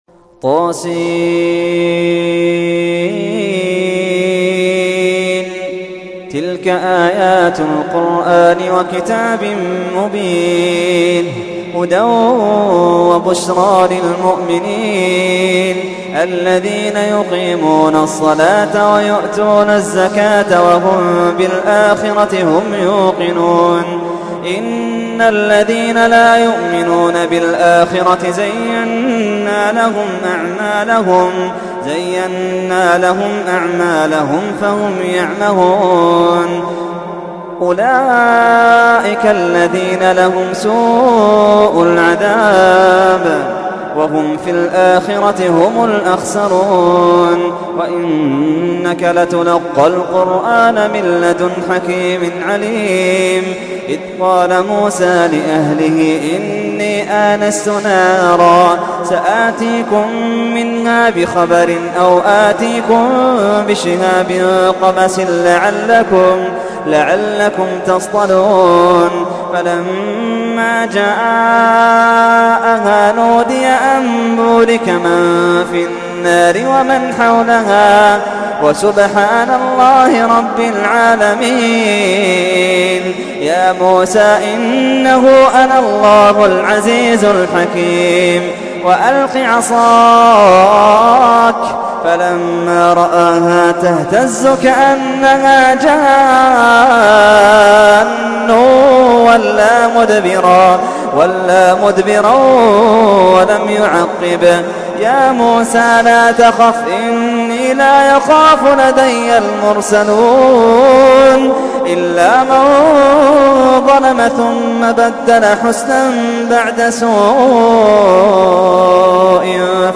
تحميل : 27. سورة النمل / القارئ محمد اللحيدان / القرآن الكريم / موقع يا حسين